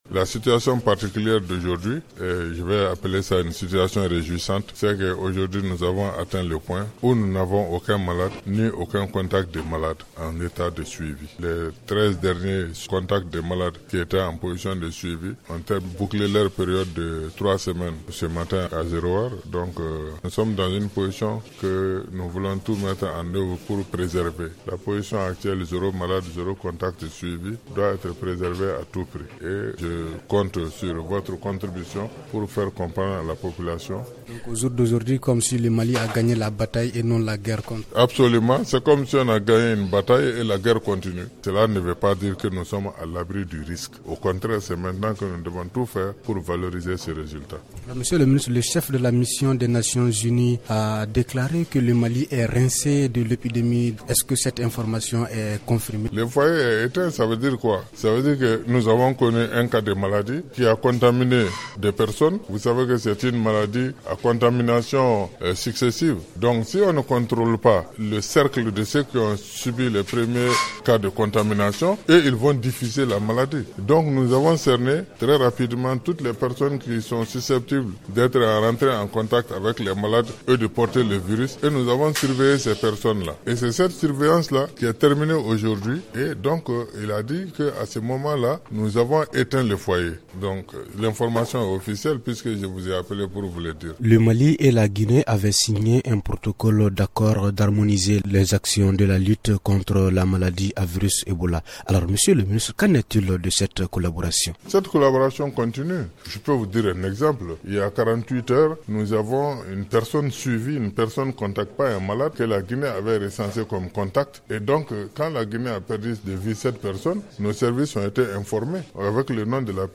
Ousmane Koné, ministre de la santé au micro